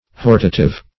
Hortative \Hor"ta*tive\, a. [L. hortativus.]